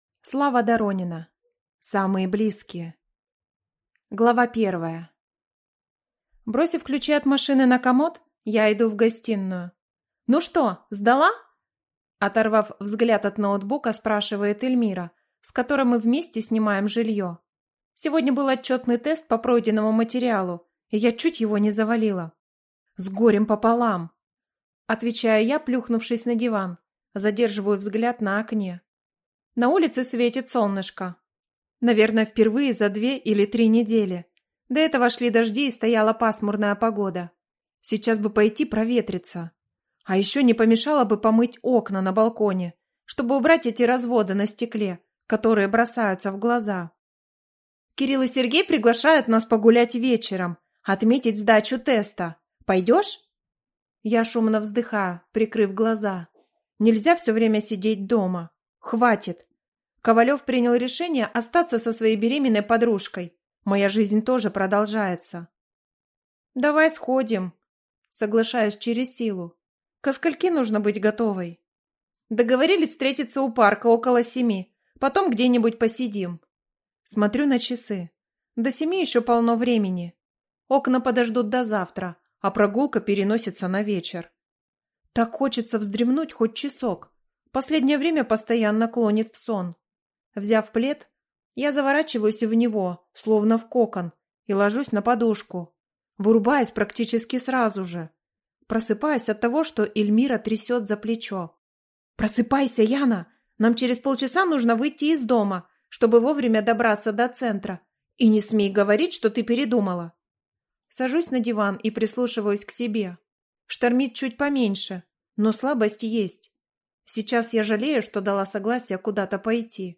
Аудиокнига Самые близкие | Библиотека аудиокниг